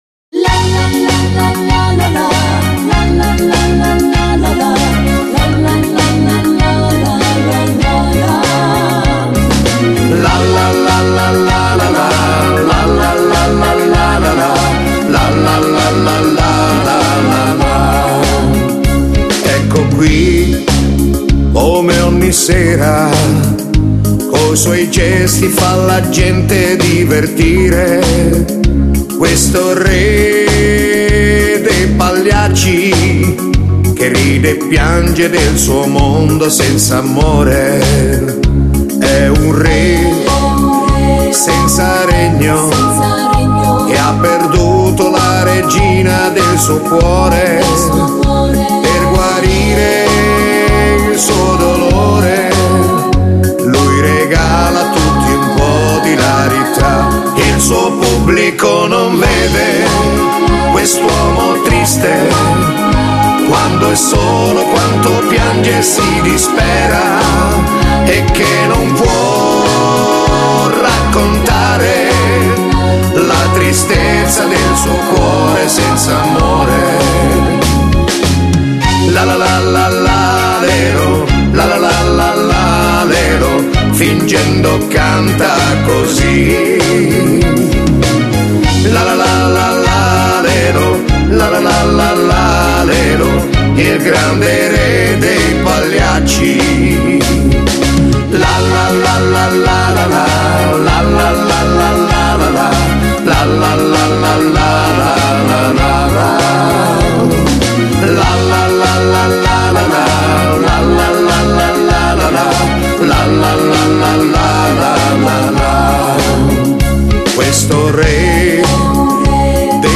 Genere: Moderato